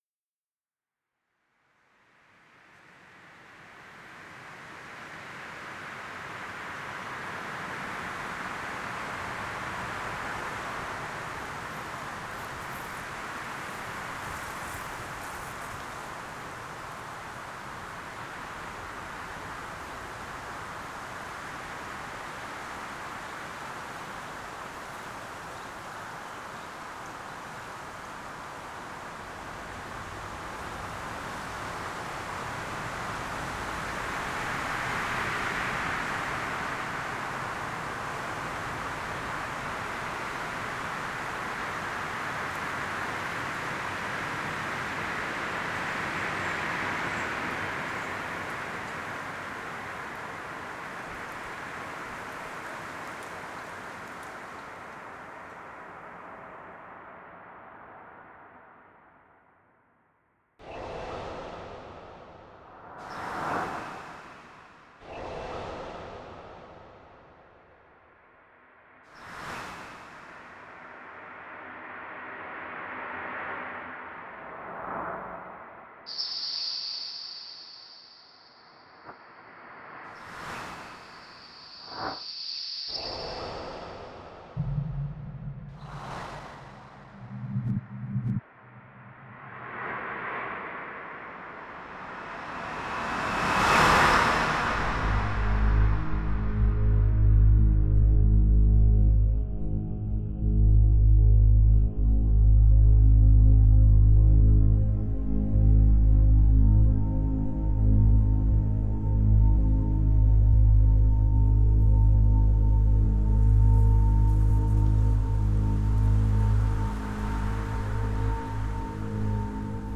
This sound is called a psithurism or a soughing of the wind through the trees. This specific wind noise was recorded on several farms around the Western Cape, where the trees are used as wind-breaks. This was achieved by positioning a pair of condenser microphones in an ORTF configuration (110° angle between them) on a very long microphone pole beneath the trees during a windy day for an hour on each farm. Through various mechanisms of sound-transformation techniques and the application of electroacoustic composition techniques in the structuring of the work Psithurism, it was possible to create long sustained tones, bell-like tones, staccato attacks, highly varied timbres and many other sound-related gestures.
This was performed at Boston, MA, USA
Noise from nature